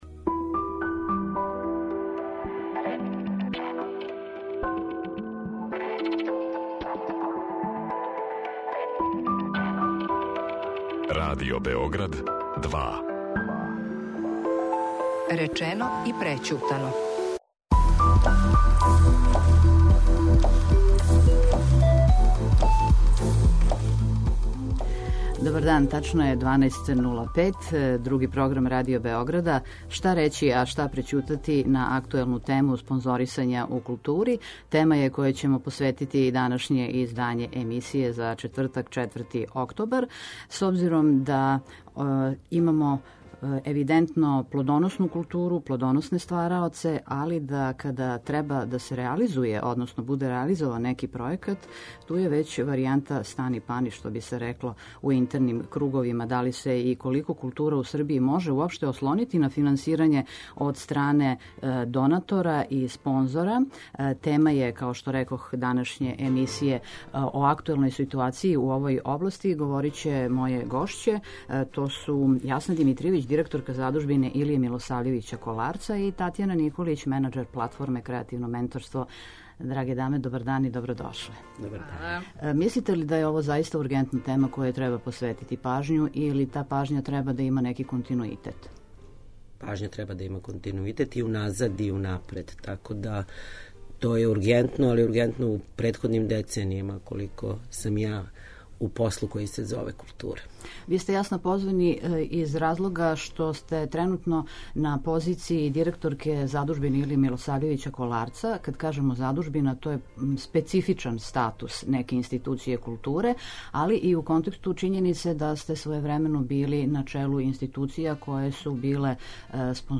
О актуелној ситуацији у тој области, подржавању културе у Србији, приоритетима, стратегијама и методима, говориће представници компанија, фондација и амбасада ‒ донатори или спонзори културних манифестација.